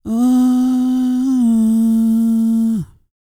E-CROON P323.wav